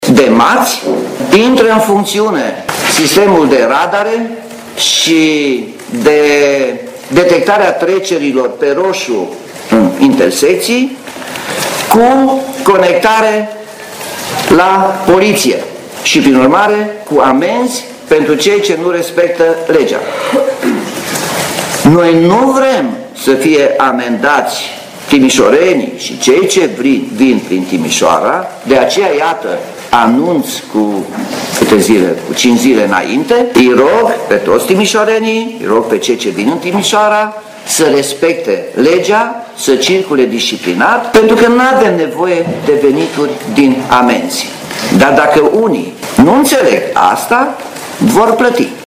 Potrivit primarului Nicolae Robu, Poliția va putea aplica inclusiv amenzi pentru viteză, șapte intersecții fiind dotate cu aparate radar: